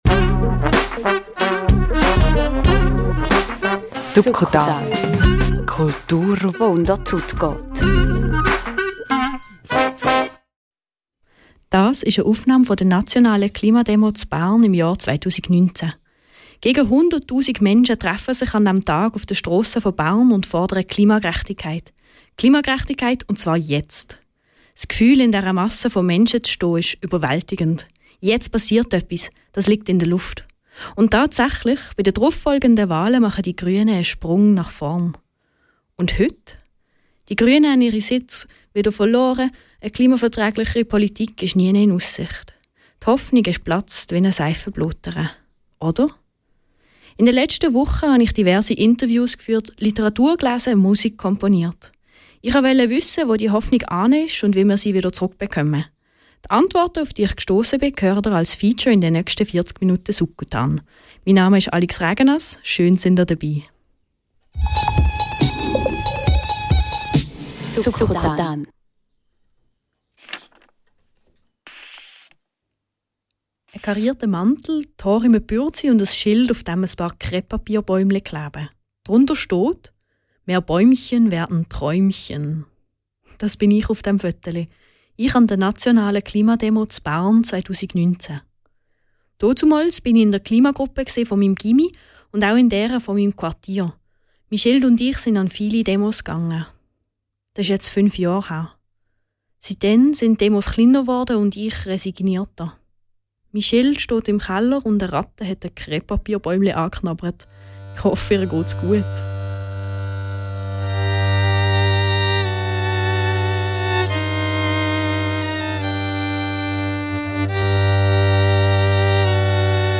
Hörstück: Hoffnung in der Klimakrise ~ Radio RaBe Podcast